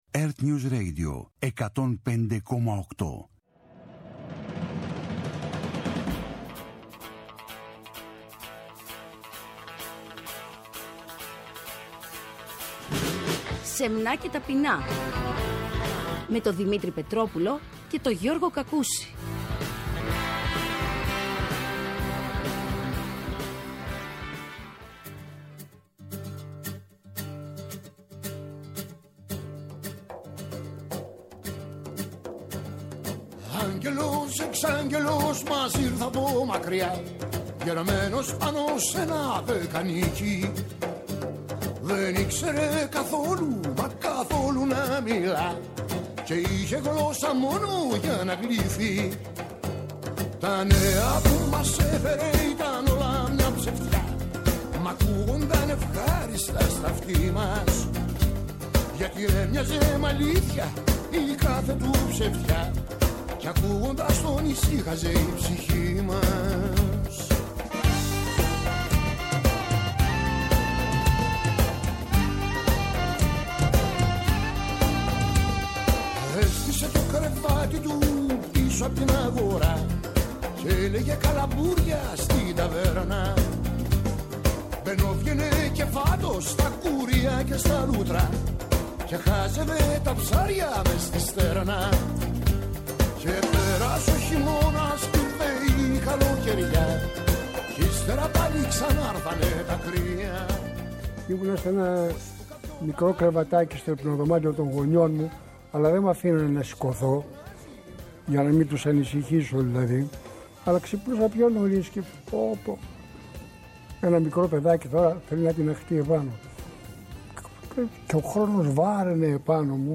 Ένα σύγχρονο ράδιο-χρονογράφημα το οποίο αποτυπώνει τον παλμό της ημέρας, τα ήθη και τις συνήθειες των ημερών μας. Η επικαιρότητα σκιτσαρισμένη με τα κανονικά της χρώματα και σκωπτική διάθεση. Ακριβώς στη μέση της εκπομπής, ο Δεκάλογος της ημέρας από τον οποίο δεν ξεφεύγει τίποτα και κανένας.